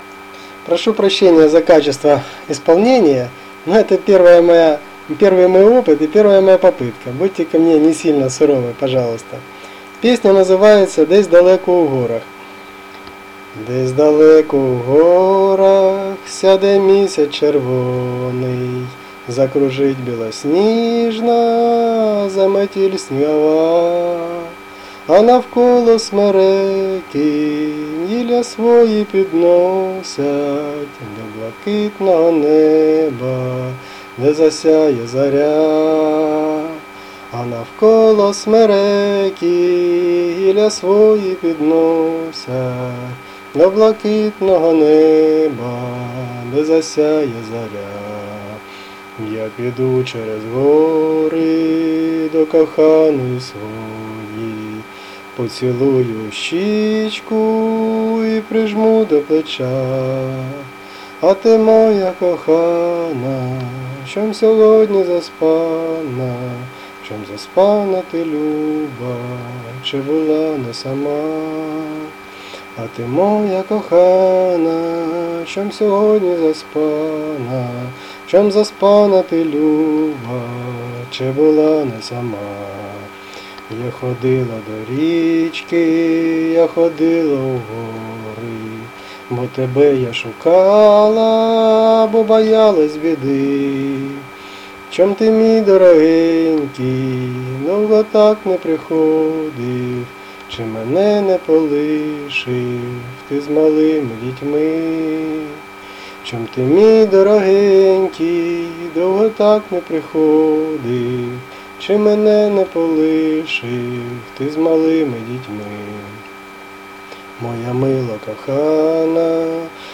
Рубрика: Поезія, Авторська пісня
П give_rose give_rose give_rose О-НАРОДНОМУ ВИЙШЛО
Схоже на народну пісню.